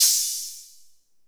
Metro Open Hat 2.wav